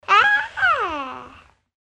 Play Risada De Bebe - SoundBoardGuy
Play, download and share risada de bebe original sound button!!!!
bebe-feliz-.MP3.mp3